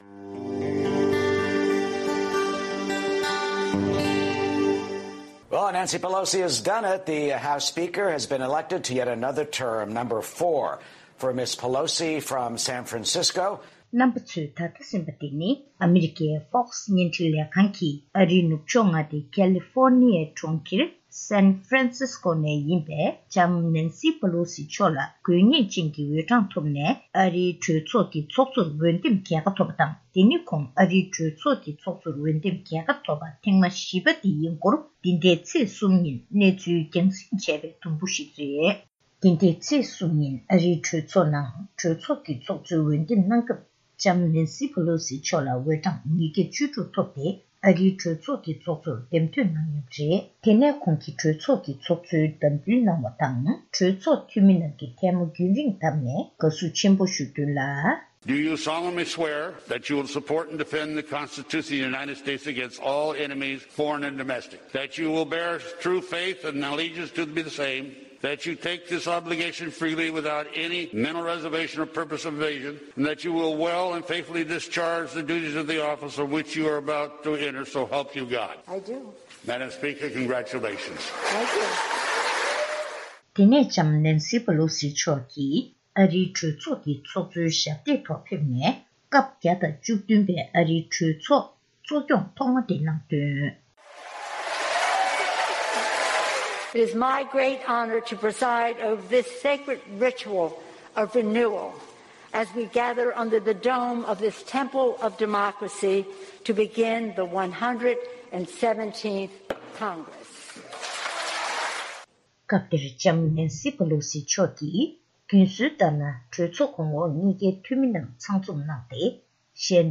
གནས་འདྲི་ཞུས་ནས་གནས་ཚུལ་ཕྱོགས་སྒྲིག་ཞུས་པ་ཞིག་ལ་གསན་རོགས་གནང་།།